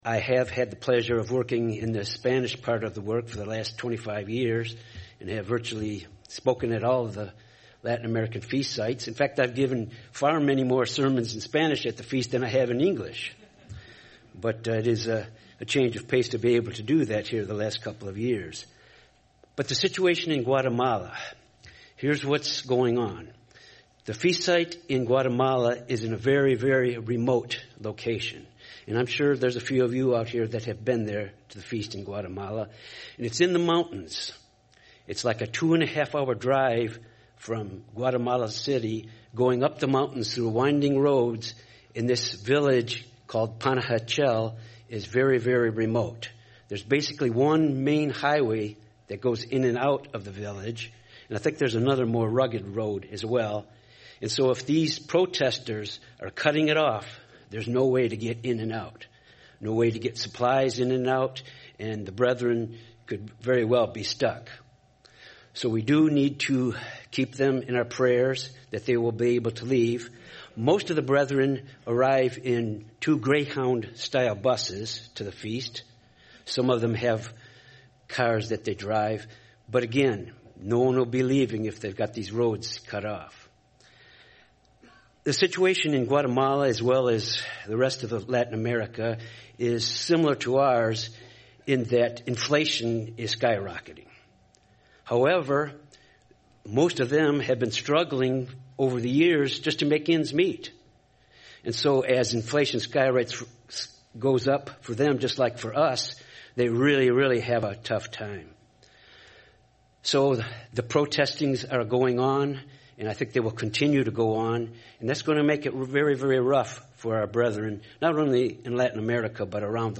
This sermon was given at the Branson, Missouri 2023 Feast site.